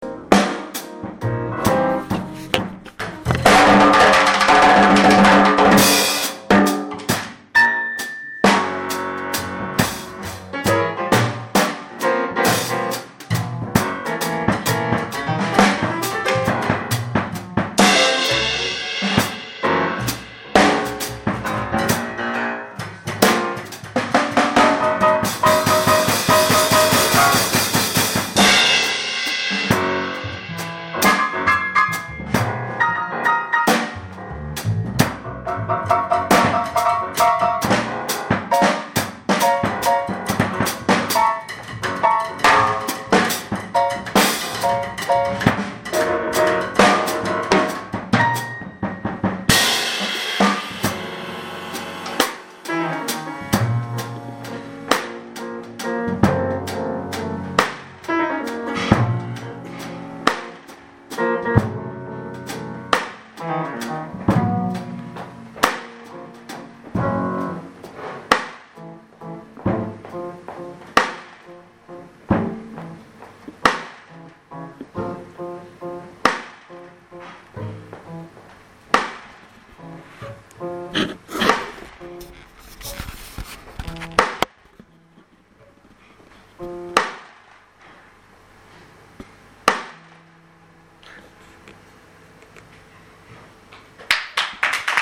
pianoforte
contrabbasso
alcuni frammenti del concerto: